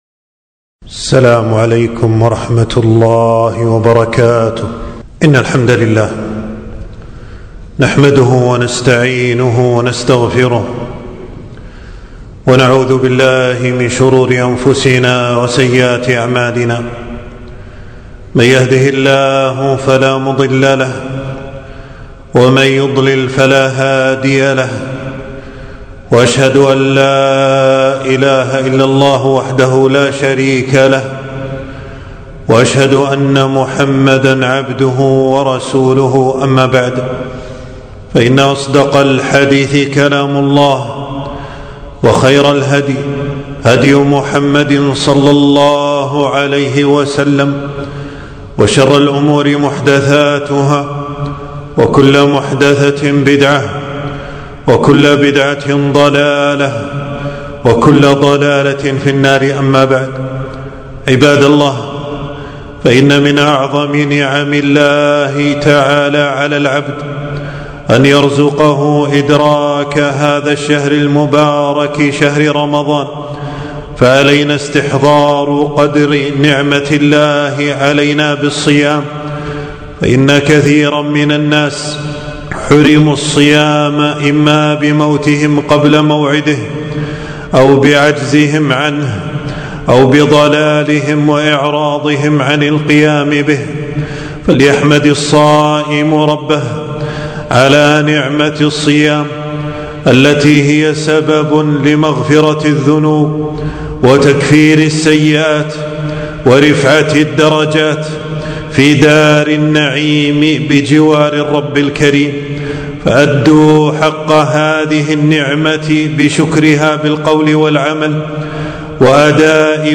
خطبة - تنبيه أهل الإسلام لأحكام الصيام